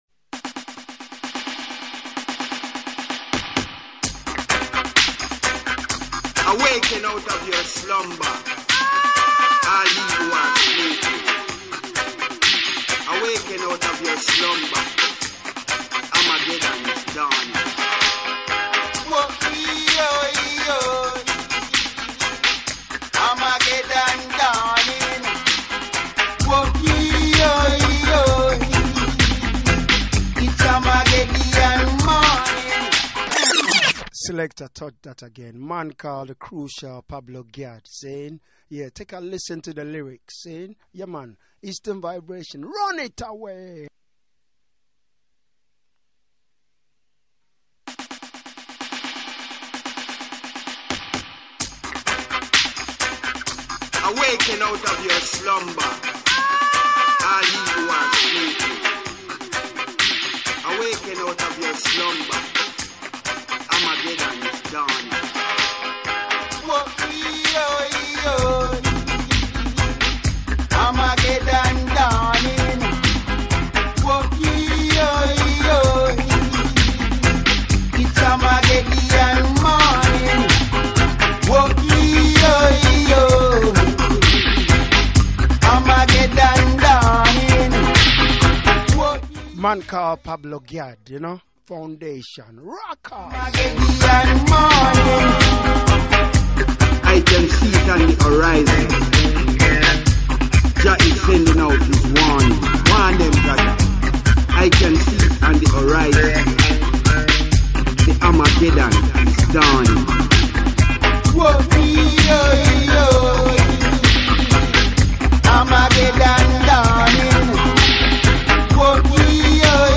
2 hours of the 4 hour radio show, sorry missed first half